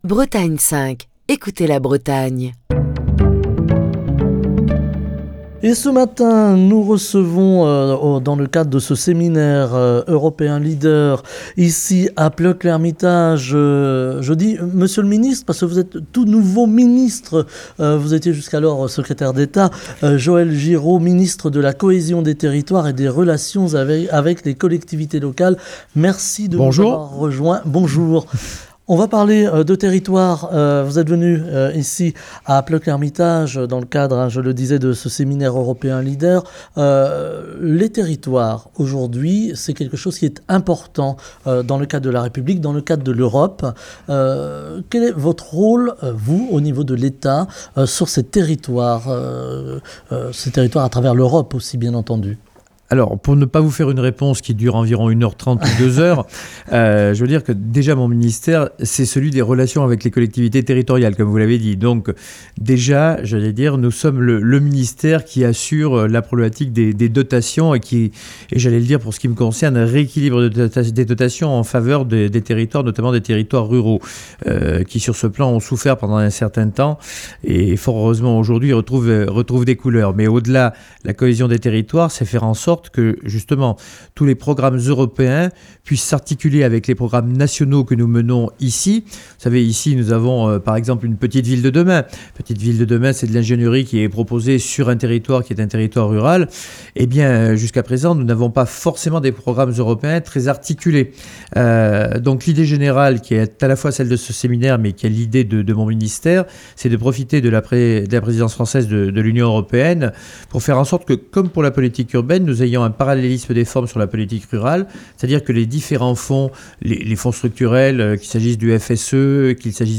Émission du 9 mars 2022. Semaine Europe et ruralité - Bretagne 5 est en direct de Plœuc-L'Hermitage pour le séminaire LEADER.